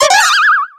80de6b019d2c31a4e30e1654ac130e3212f110df infinitefusion-e18 / Audio / SE / Cries / MIMEJR.ogg infinitefusion d3662c3f10 update to latest 6.0 release 2023-11-12 21:45:07 -05:00 10 KiB Raw History